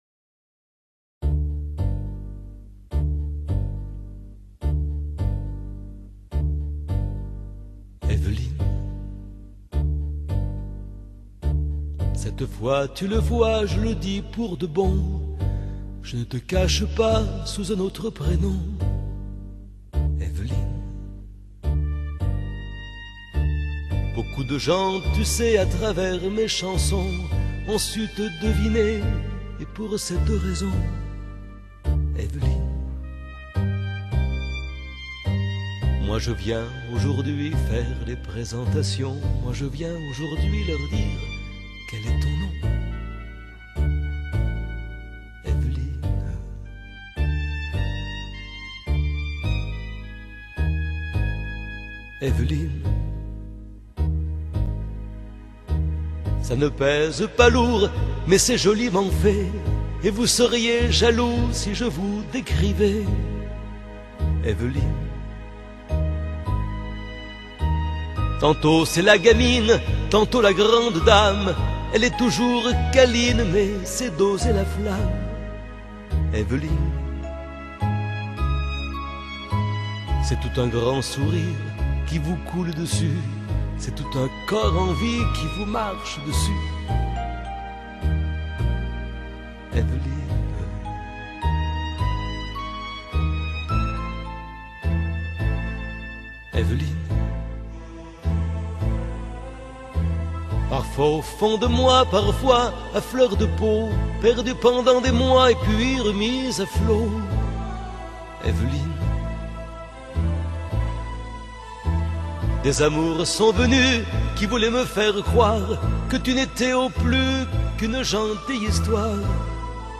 ФРАНЦУЗСКИЙ ШАНСОН